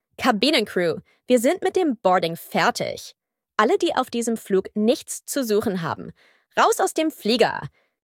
BoardingComplete.ogg